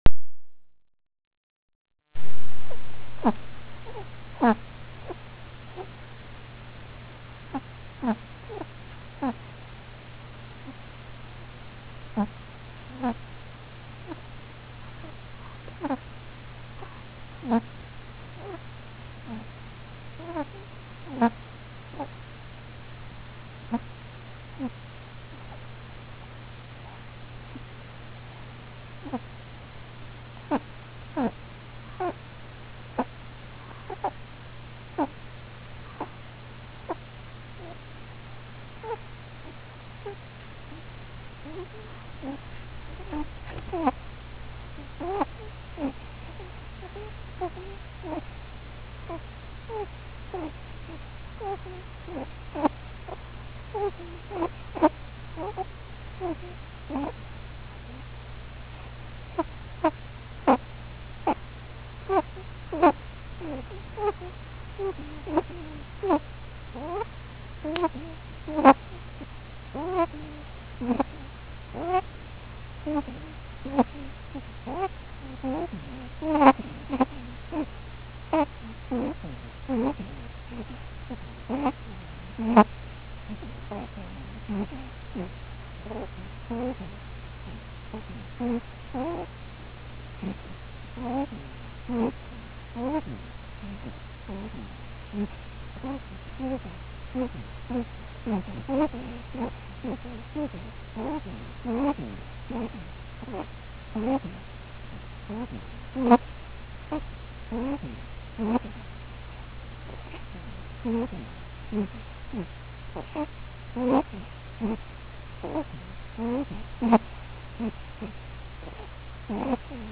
胸のポケットに入れっぱなしだった仕事道具のMP3プレーヤーで録音。なかなか良く取れている(笑)。
（それにしても、うさぎというのはこんなに鳴くものだろうか……（汗）
うちのうさぎは、耳の付け根をなでつけると、よくこういう声を出すのだが……）